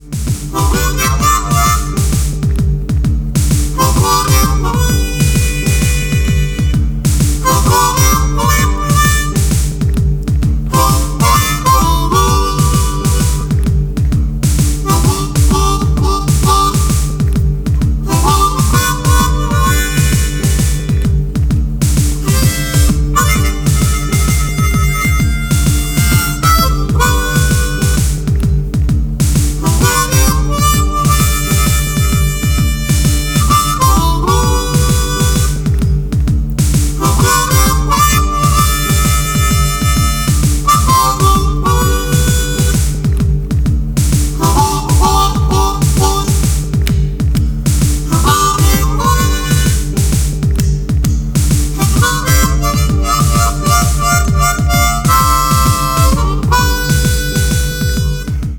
Bluesharph Solo mit Pulsierendem Shuffle Loop, epic.